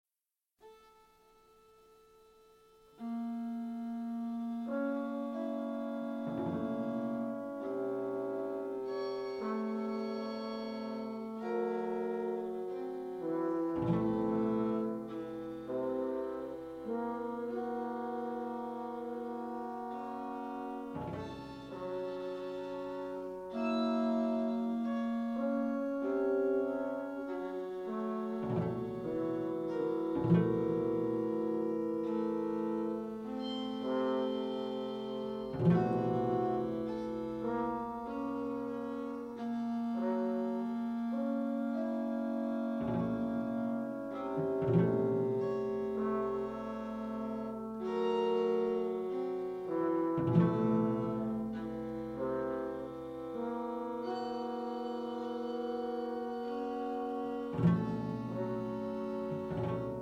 horn